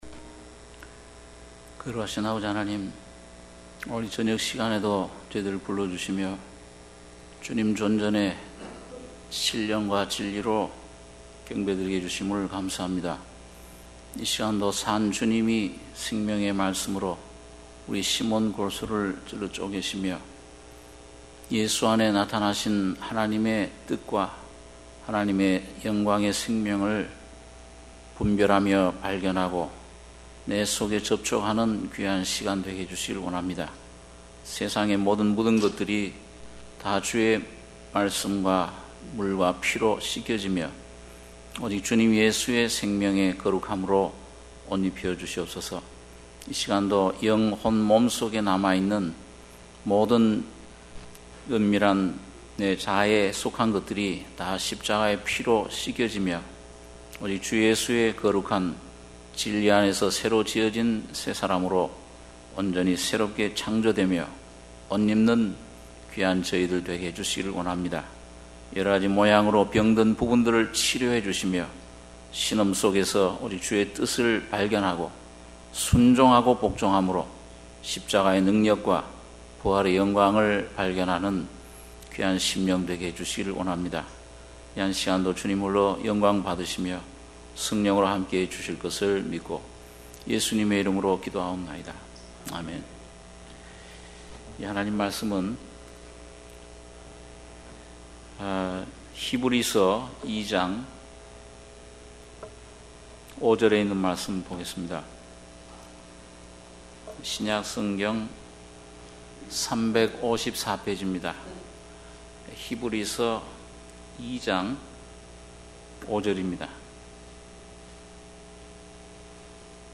수요예배 - 히브리서 2장 5-10절